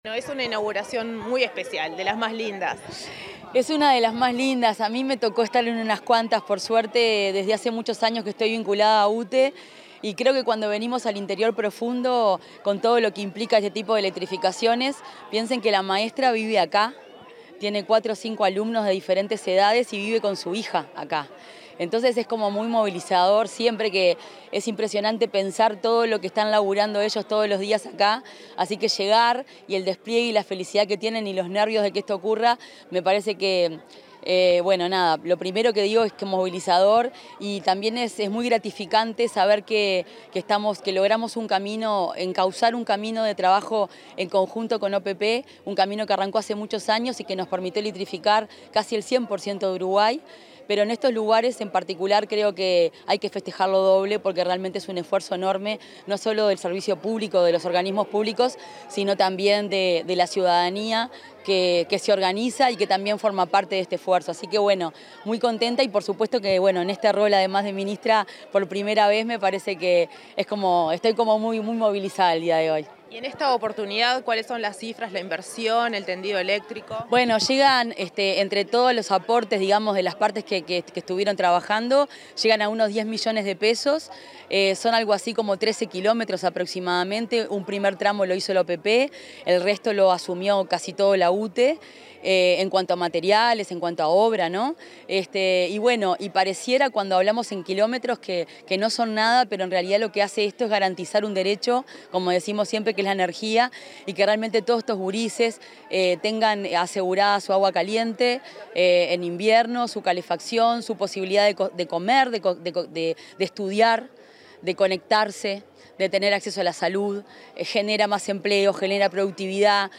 Declaraciones de la ministra de Industria, Fernanda Cardona
Declaraciones de la ministra de Industria, Fernanda Cardona 16/09/2025 Compartir Facebook X Copiar enlace WhatsApp LinkedIn Previo a la inauguración de las obras de electrificación rural en la localidad de San Benito, Tacuarembó, la ministra de Industria, Energía y Minería, Fernanda Cardona, se expresó acerca del impacto de esta política pública en la población local.